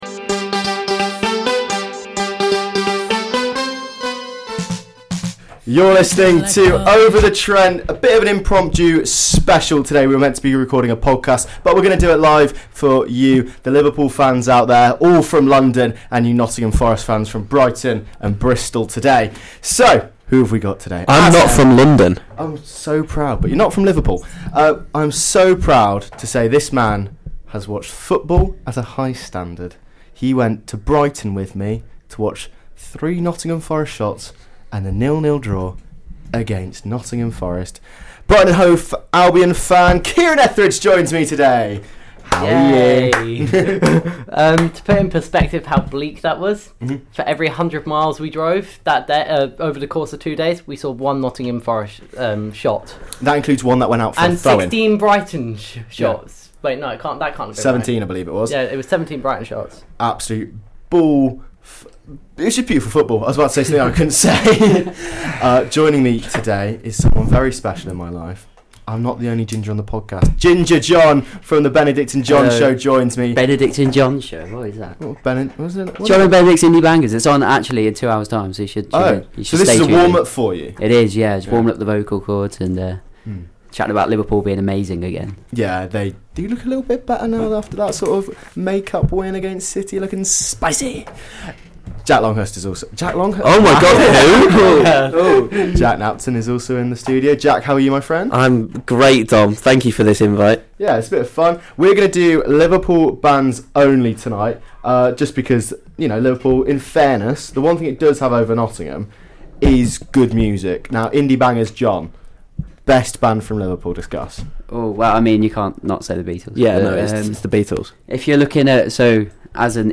A slightly chaotic live show from Thursday but we hope you enjoy the podcast, previewing the big 80s clash between Nottingham Forest and Liverpool!